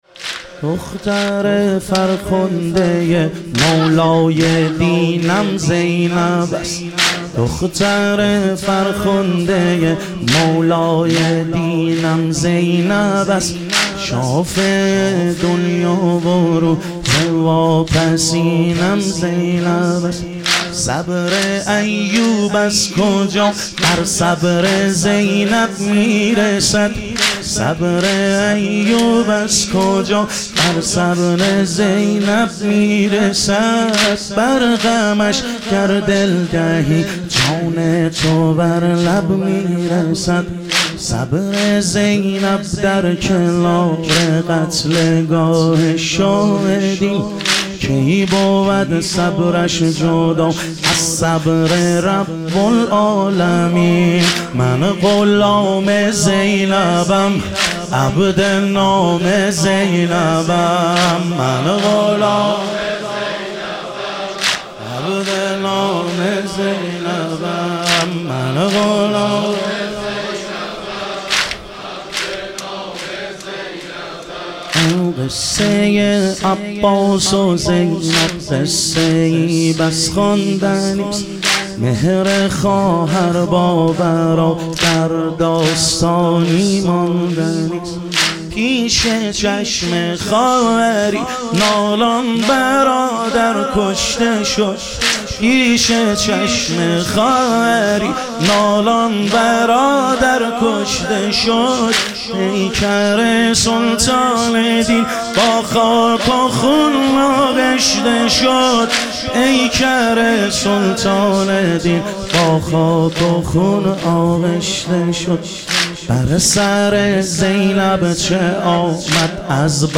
شهادت حضرت زینب کبری علیها سلام - واحد